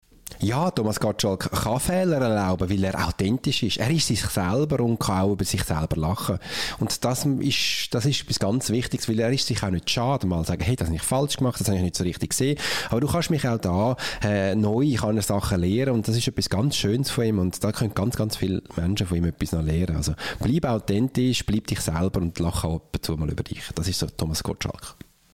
Dieses Interview gibt es auch auf Deutsch!